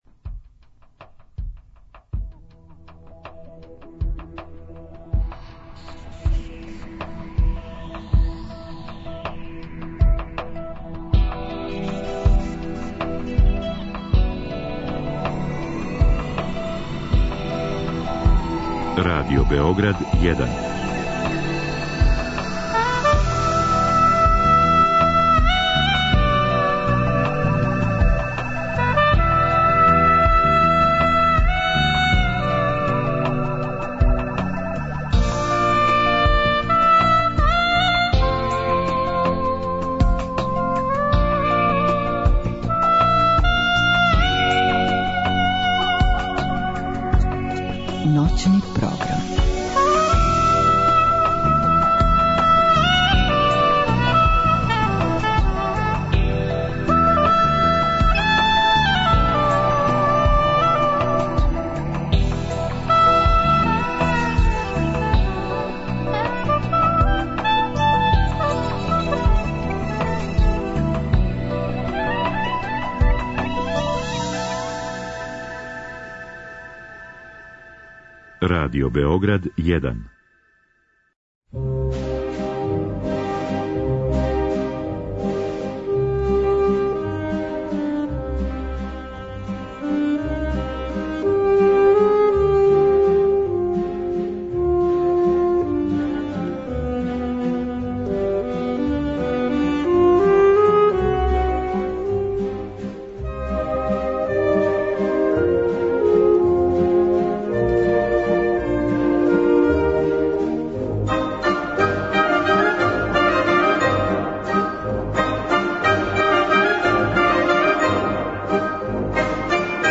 У последњем сату Ноћног програма уметничке музике (03-04), емитоваћемо композиције Монкаја, Виља-Лобоса, Маркеза и Рамиреза.